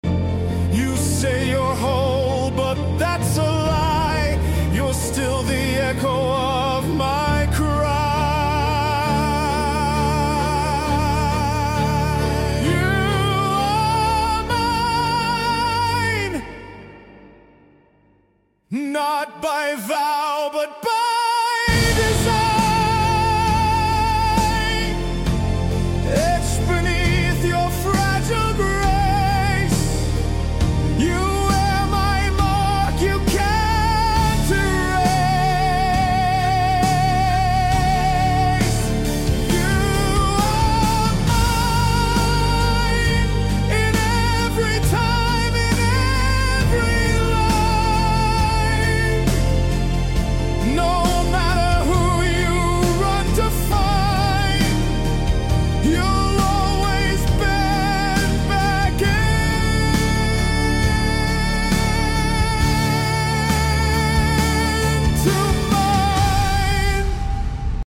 dark masculine ballad